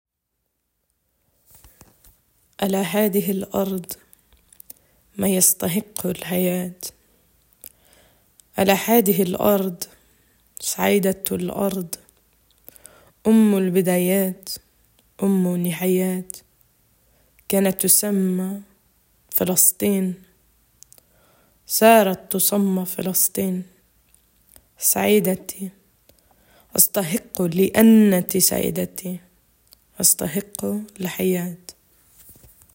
Sie wählte aus dem Gedicht «On This Land» des palästinischen Dichters Mahmoud Darwish die letzte Strophe: